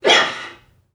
NPC_Creatures_Vocalisations_Robothead [2].wav